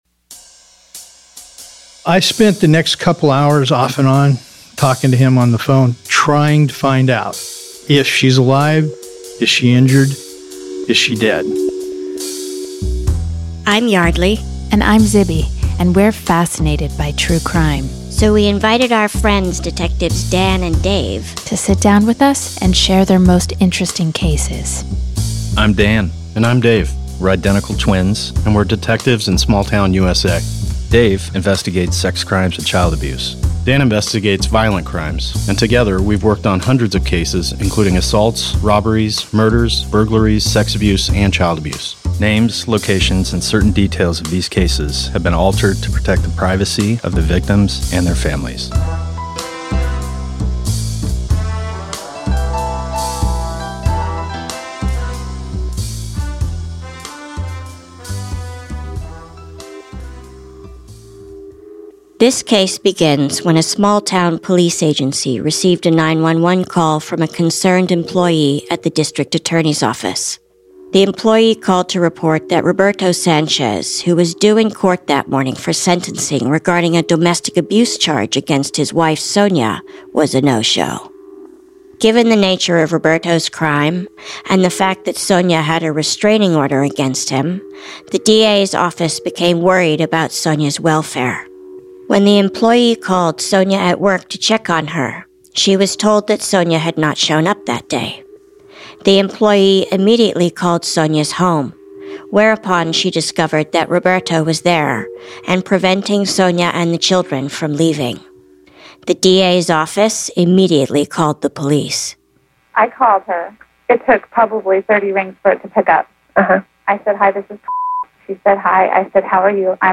Detectives recount the hours-long stand-off and its impossible ending.